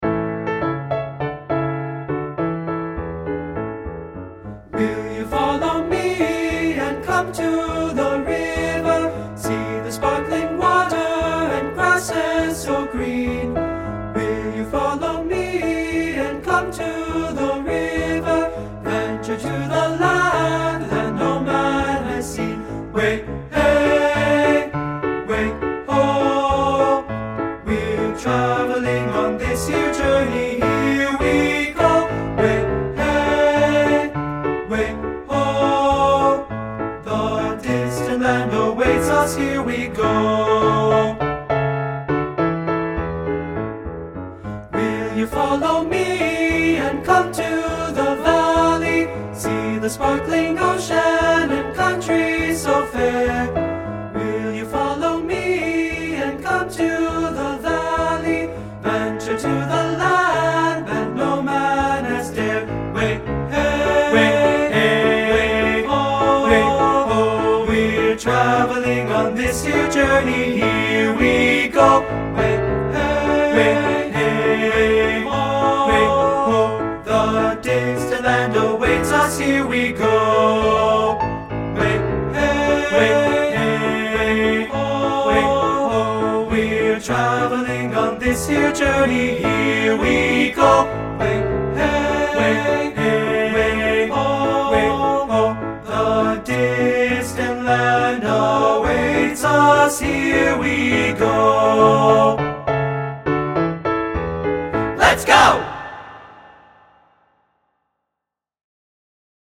• Tenor 1
• Tenor 2
• Piano
Studio Recording
Ensemble: Tenor-Bass Chorus
Key: A major
Tempo: Adventurous (q = 102)
Accompanied: Accompanied Chorus